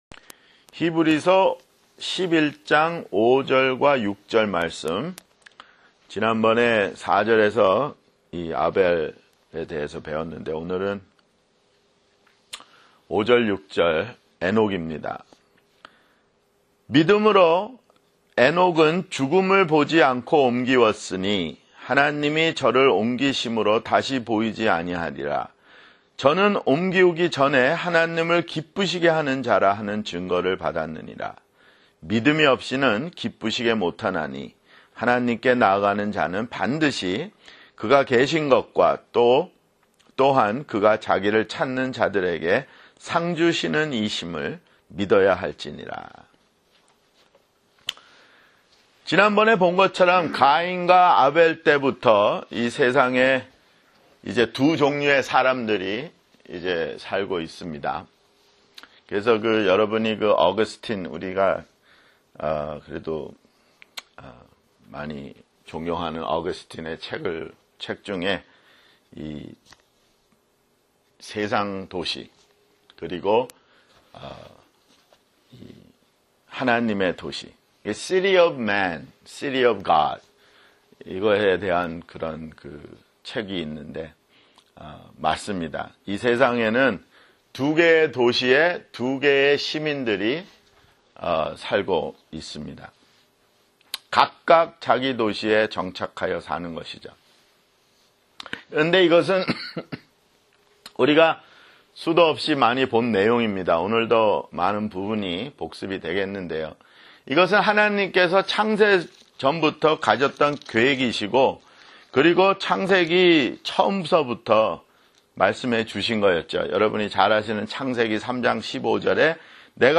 [성경공부] 히브리서 (41)